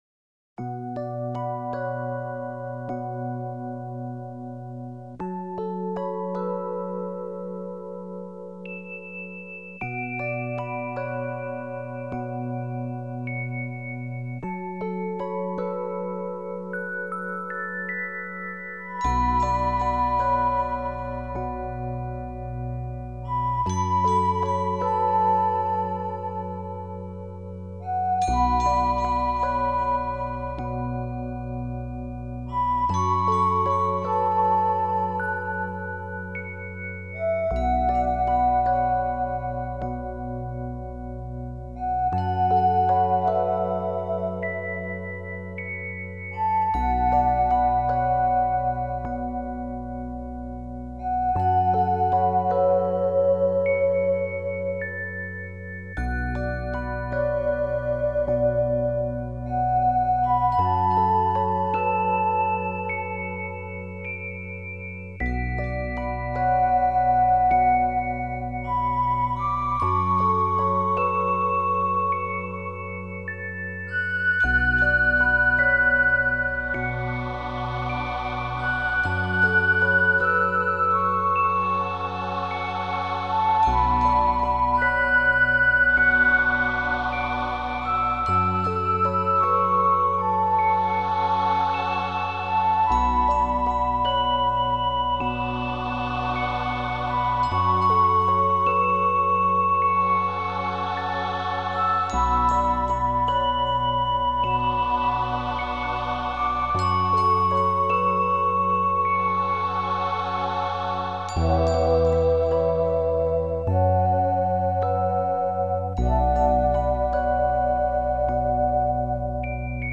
纯音乐演绎
他的音乐风格具有浓厚的民族风味